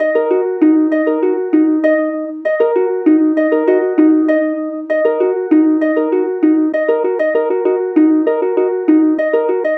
Rock Star - Harp.wav